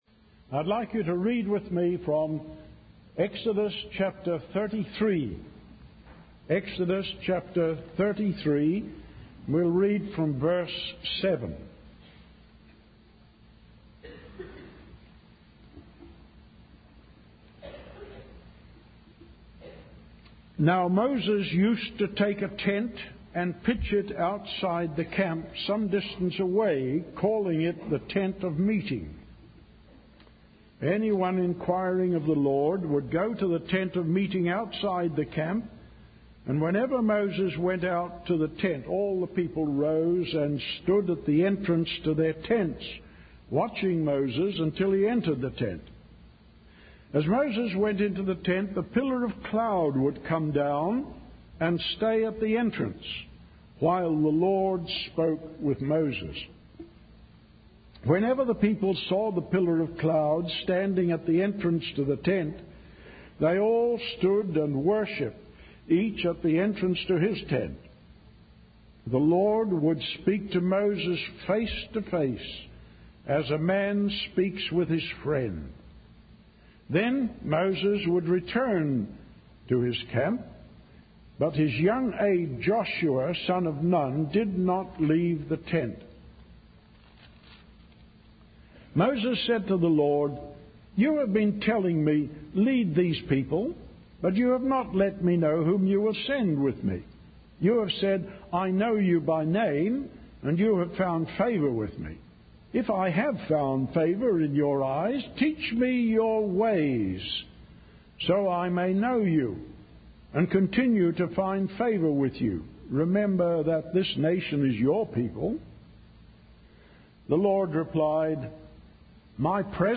In this sermon, the speaker emphasizes the importance of spending time alone with God. He uses the example of Moses, who spent six days alone with God on the mountain and became radiant as a result.